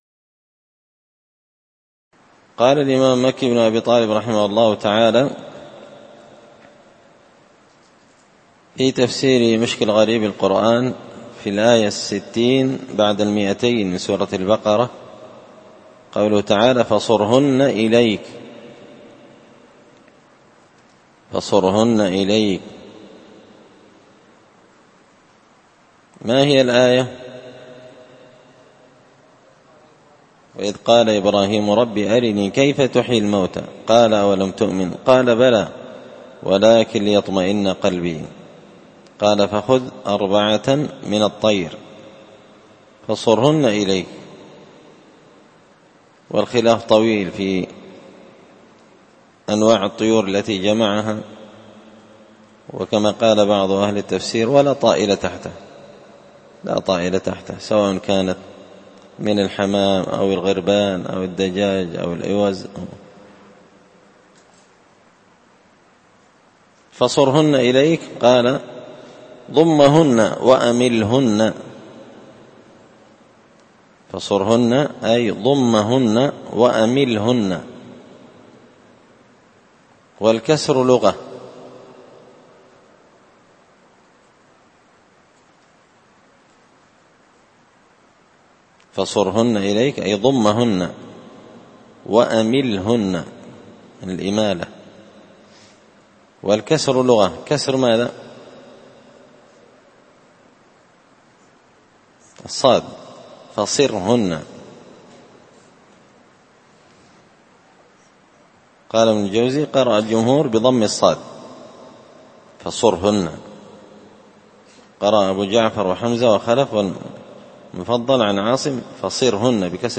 تفسير مشكل غريب القرآن ـ الدرس 49
دار الحديث بمسجد الفرقان ـ قشن ـ المهرة ـ اليمن